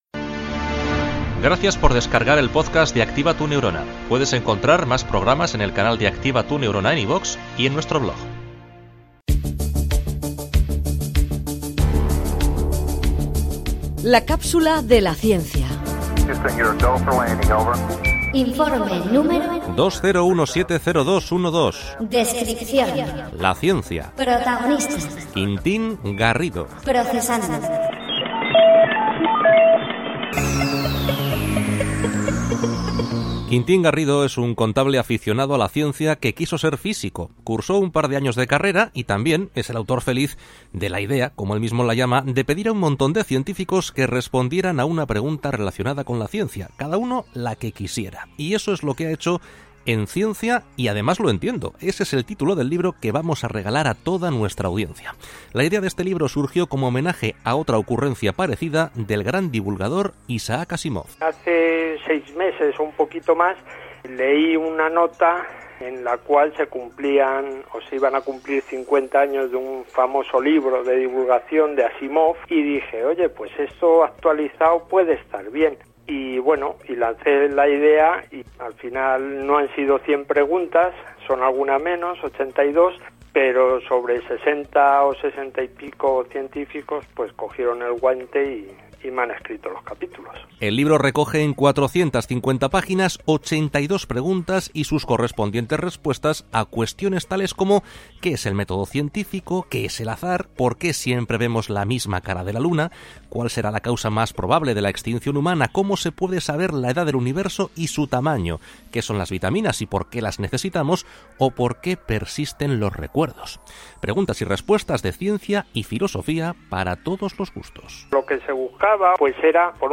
La comunicación de la ciencia es la protagonista de 3 minutos de radio en los que colaboramos con científicos punteros para contar de forma amena y sencilla los resultados de sus últimas investigaciones.